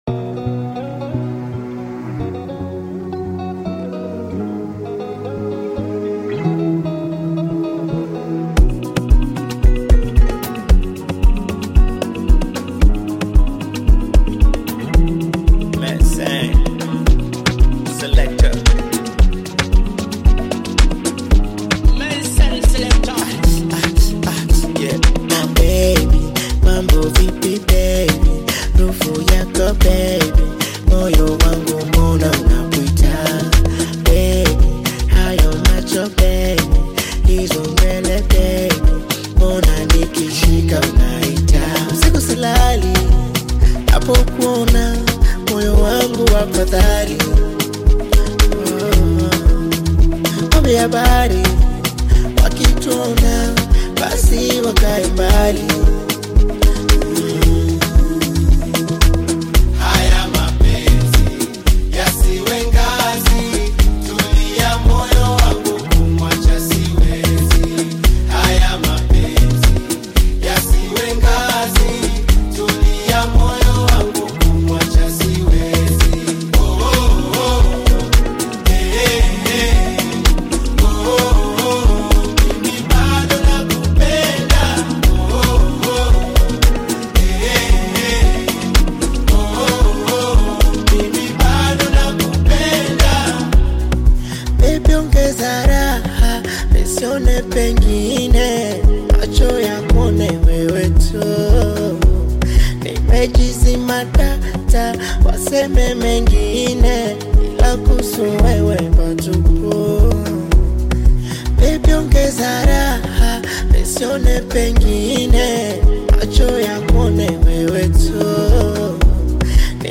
The song has a catchy melody and a positive message.
Bongo Flava